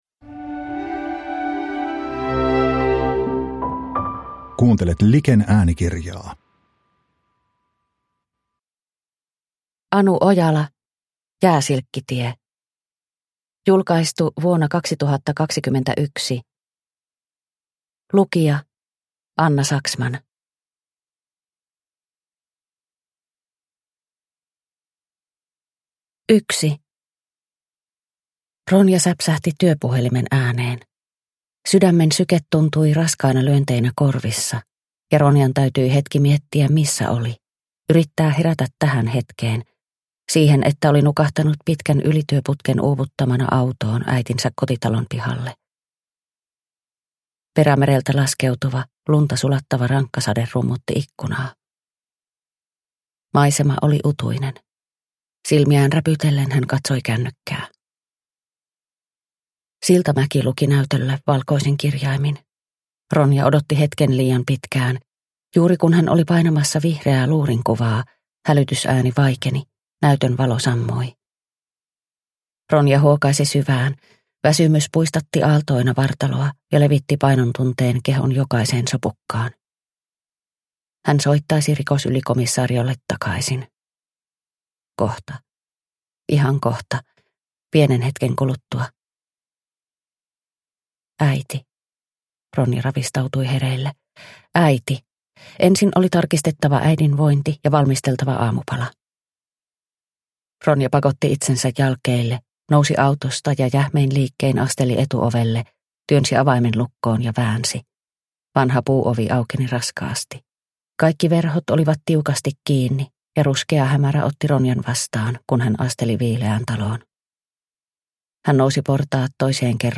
Jääsilkkitie – Ljudbok – Laddas ner